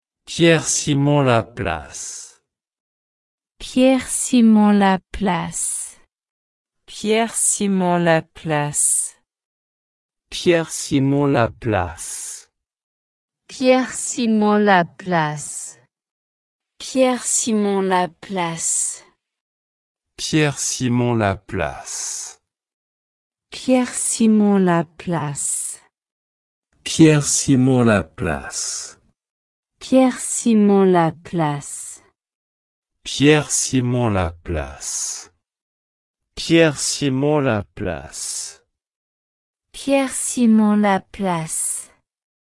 Pronunciation_Pierre-Simon_Laplace.ogg